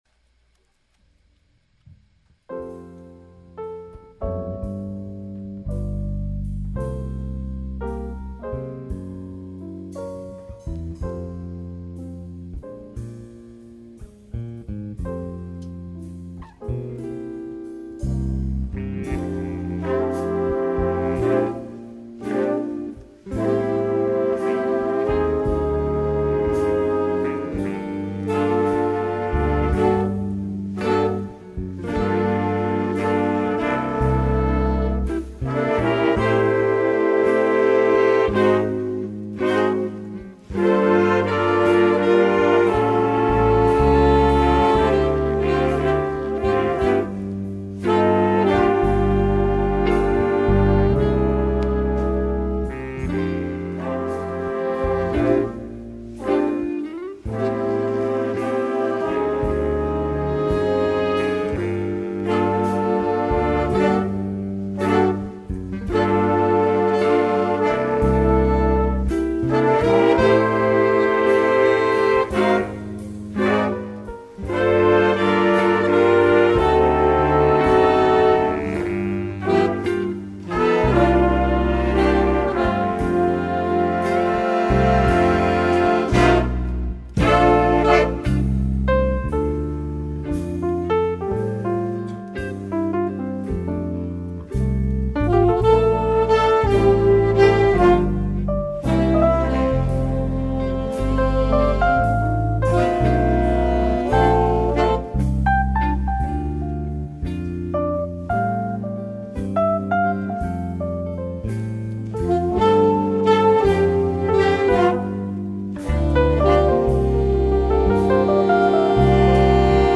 ballad style
jazz ensemble
Written piano solo is provided.